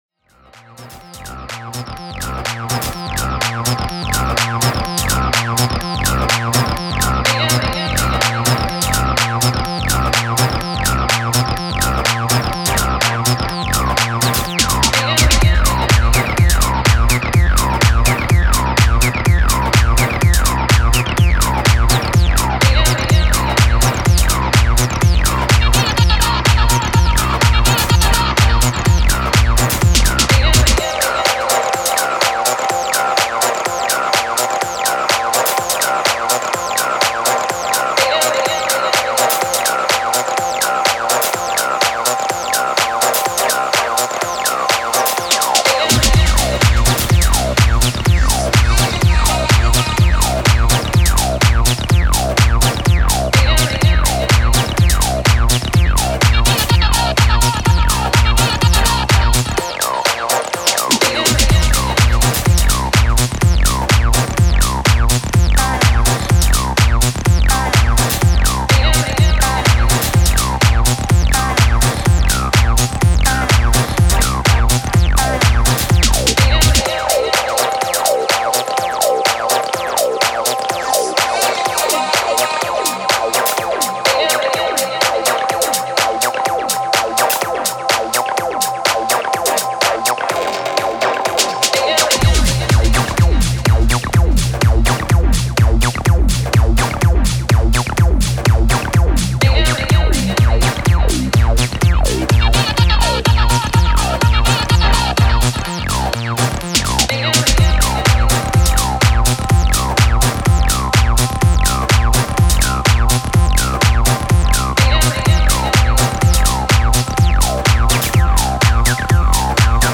I’m a fan of the T-8’s Bass synth - sync’d it up to my OT and came up with a couple of loopy things :slight_smile: